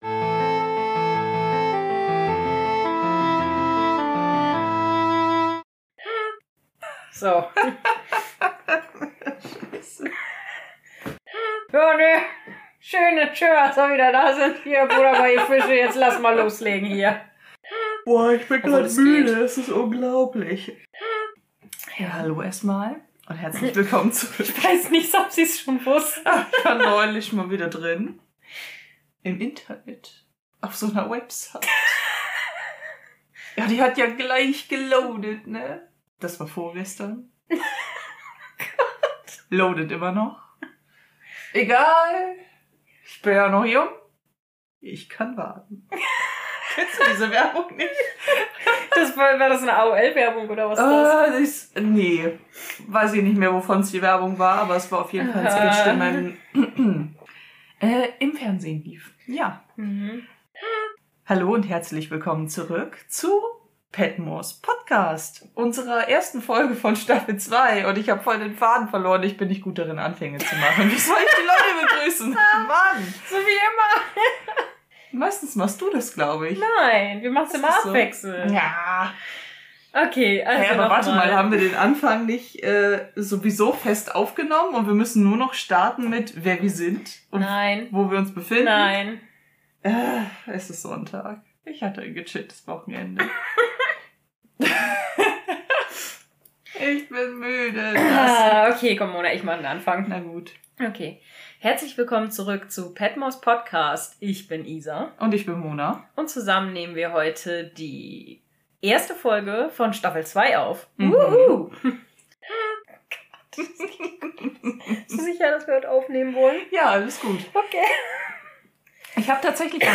Hier die Outtakes zu Staffel 2, Folge 1 Part 1.